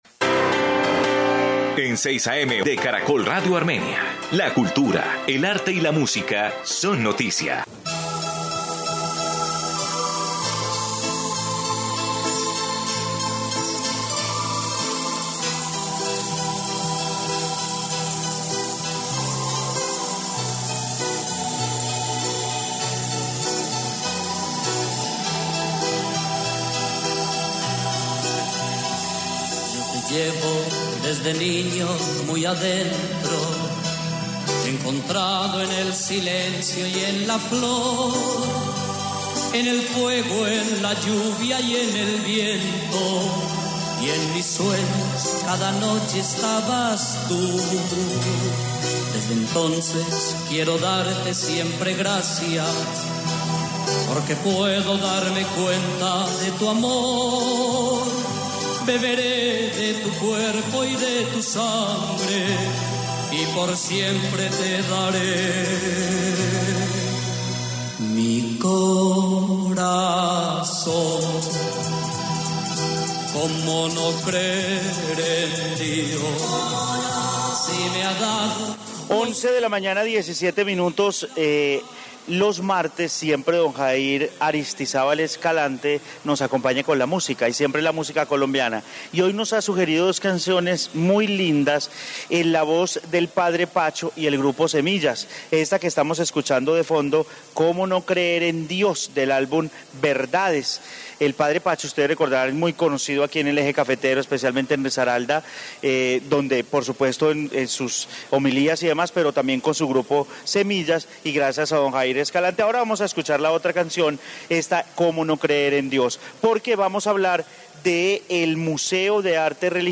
Informe Museo de Arte Religioso de Armenia